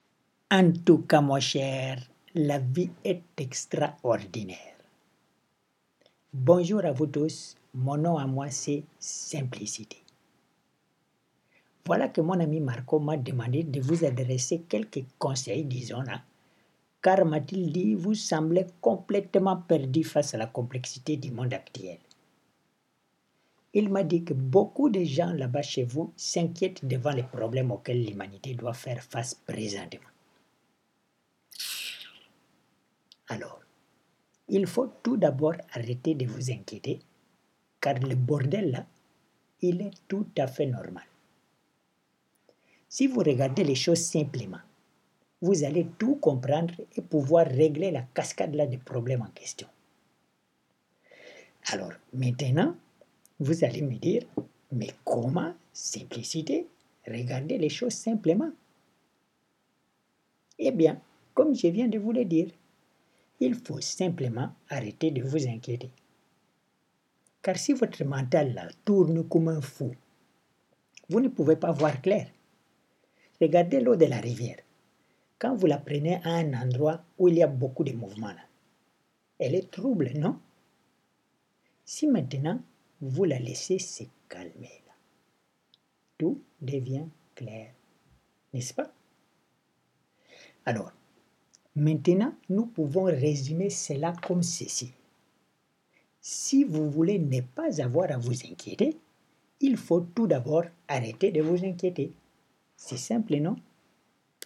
Donner la parole à Simplicité avec un fort accent africain pouvant aisément prêter à la dérision est pour moi une façon de rendre justice à de magnifiques personnes rencontrées là-bas et qui, comme lui, ont pu, peuvent ou pourraient, à tort, passer pour de doux innocents en raison de l’originalité de leur style lorsqu’ils s’expriment dans nos langues.